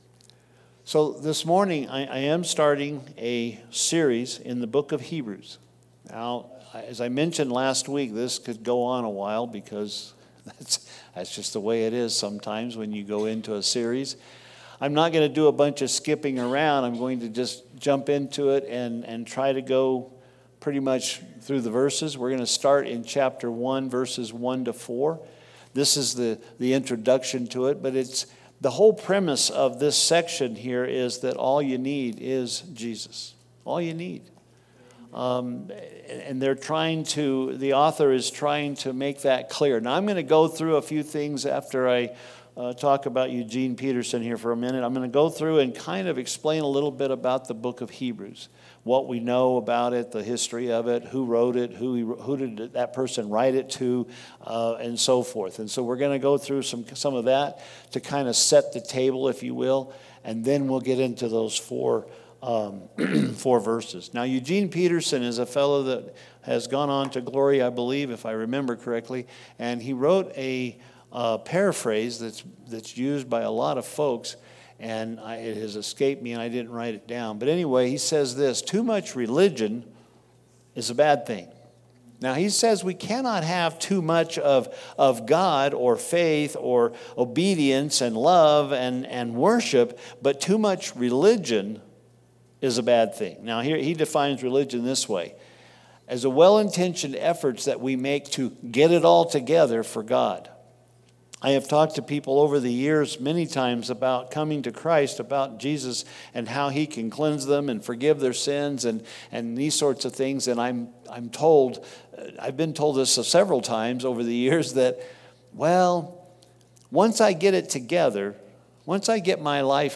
This message introduces the beginning of Hebrews and sets the stage, as the sermon is entitled, with the foundation of it all: Jesus.